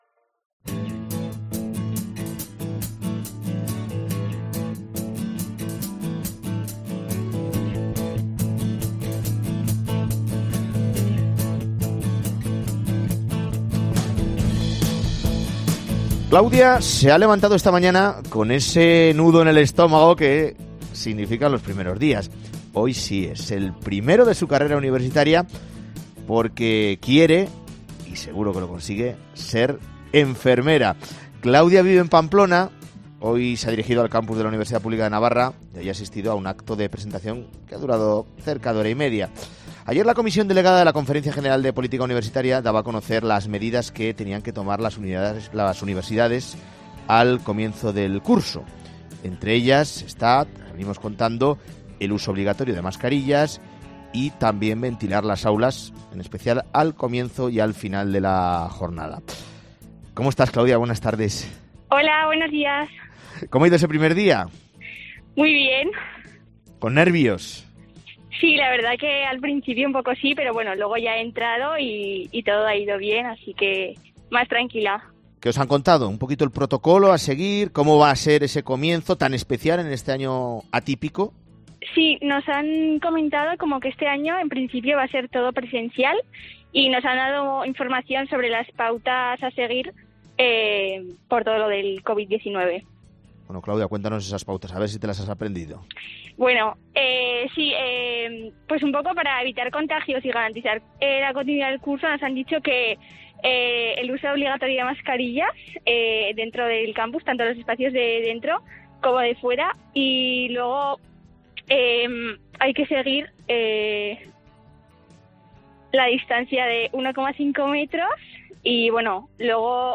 Lo comprobamos con un alumno y un profesor, en una universidad pública y en la privada.